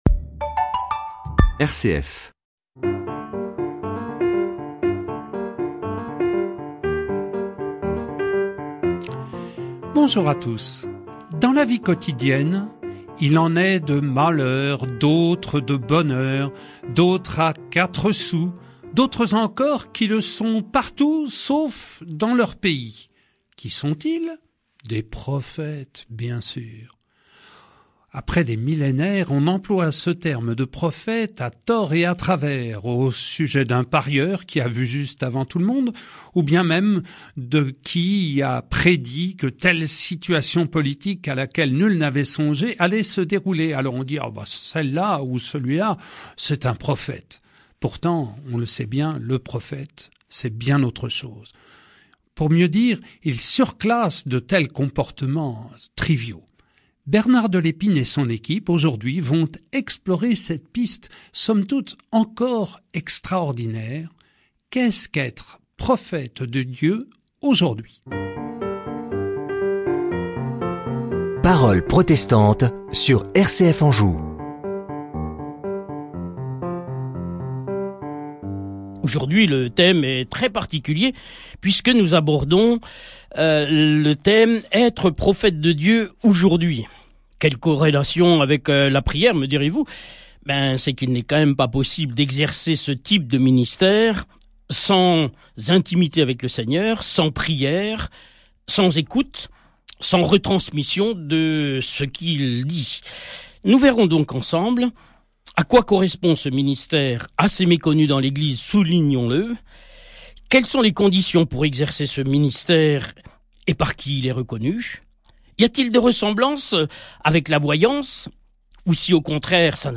Séries : Emission de radio RCF – Parole Protestante | Etre prophète de Dieu aujourd’hui, est-ce possible et n’est-ce pas prétentieux ?